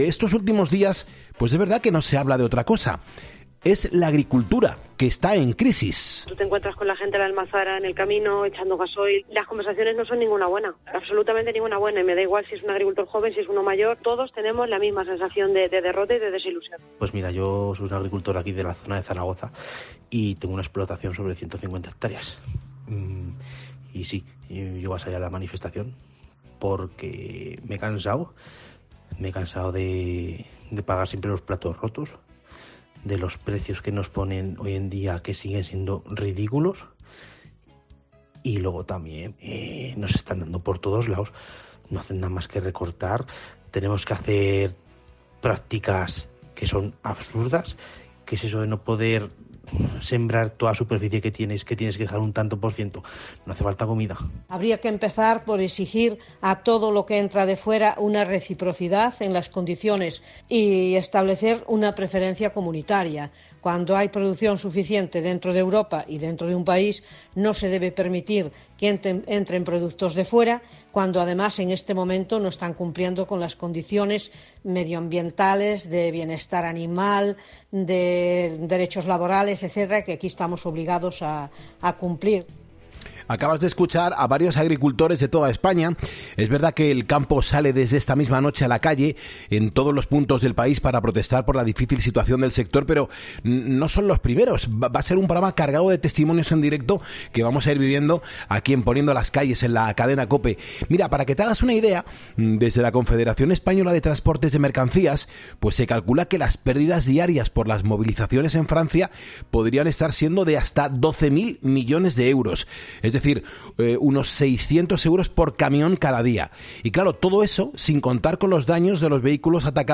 charla con